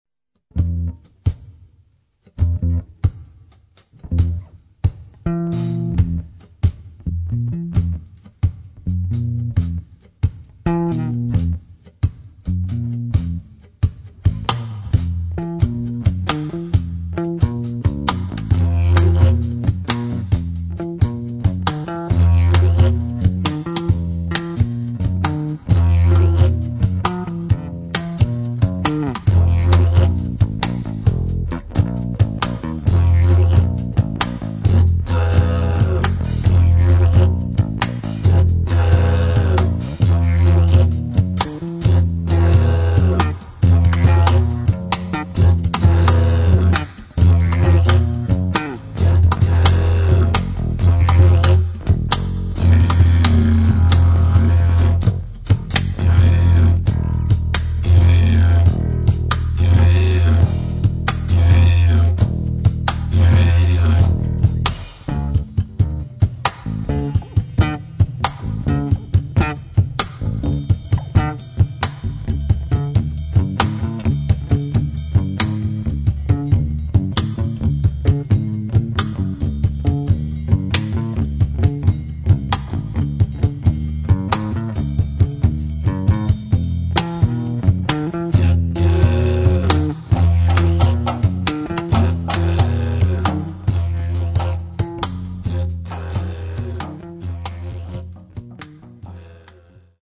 Didgeridoo und mehr